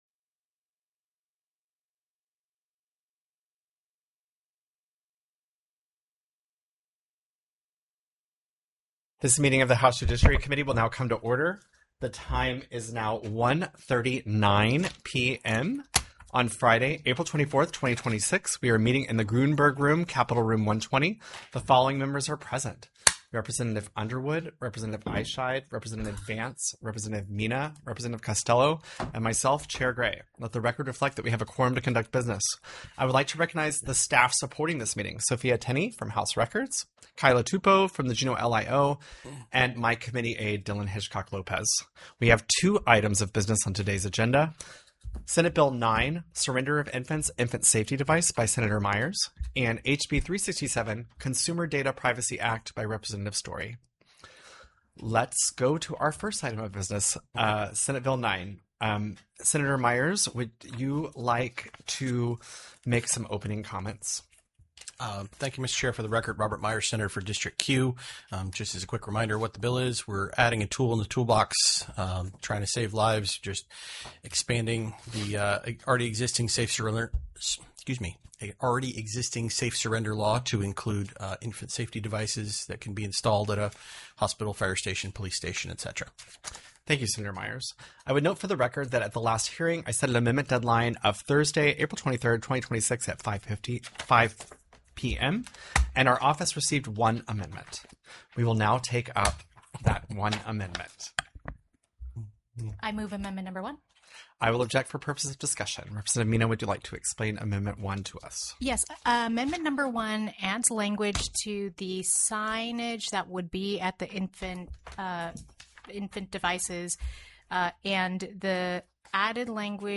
The audio recordings are captured by our records offices as the official record of the meeting and will have more accurate timestamps.
HB 367 CONSUMER DATA PRIVACY ACT TELECONFERENCED